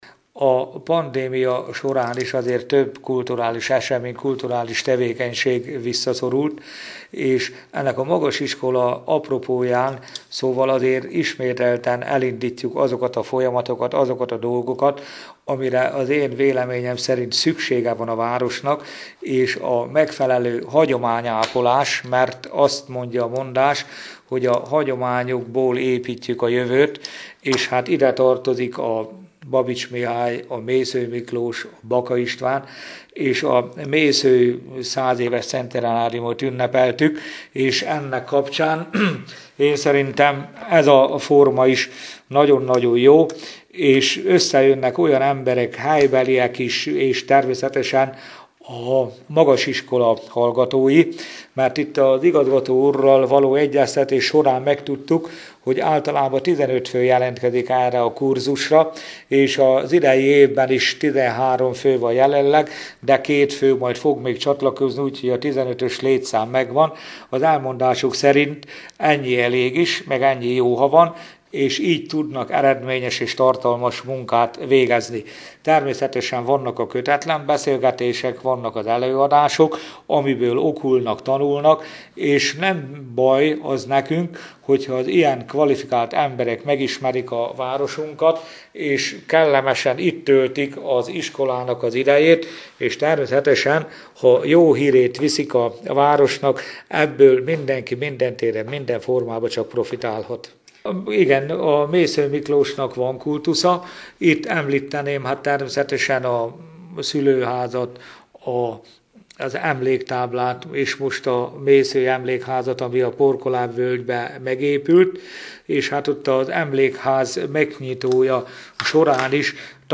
Tizenhárom fő részvételével hétfőn elkezdődött a VIII. Szekszárdi Magasiskola Mészöly Miklós Irodalmi Akadémia és Írói Mesterkurzus. A résztvevőket Gyurkovics János alpolgármester köszöntötte a...